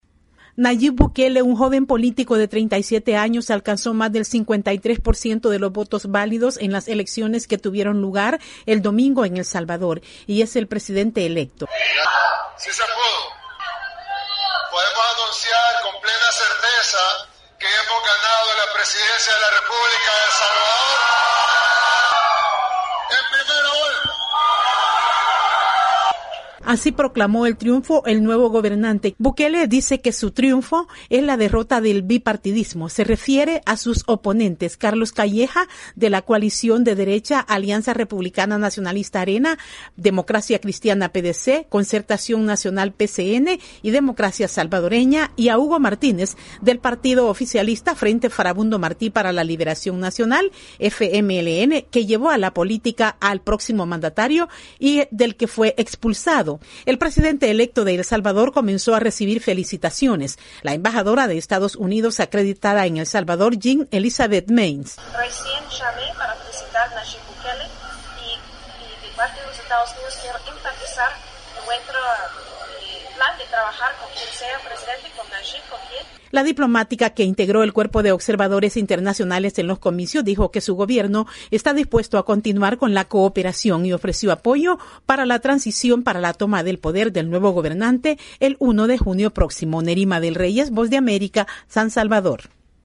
VOA: Informe desde El Salvador